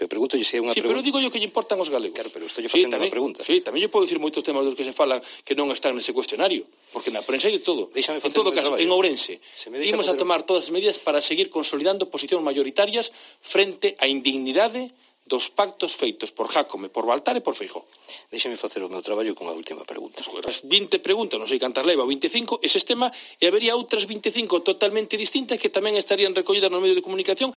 El líder del PSOE en Galicia abronca a un periodista porque no le gustan las preguntas